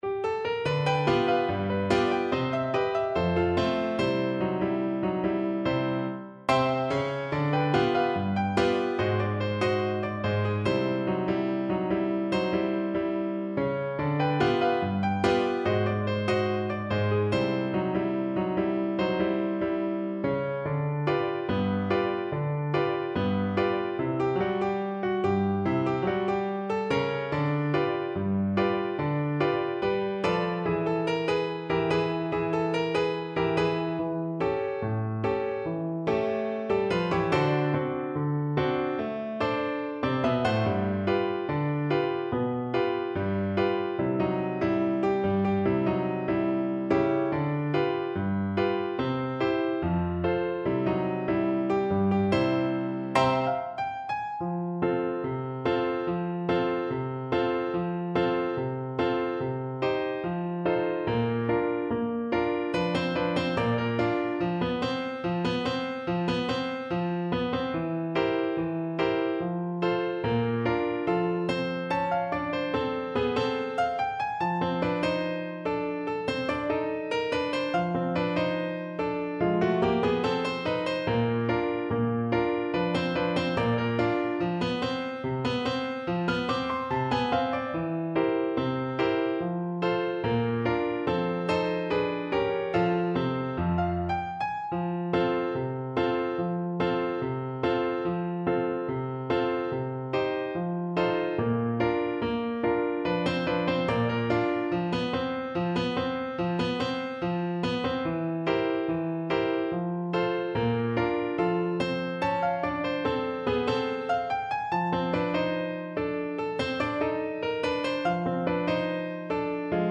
Xylophone
= 72 Moderato
2/4 (View more 2/4 Music)
G5-C7
Jazz (View more Jazz Percussion Music)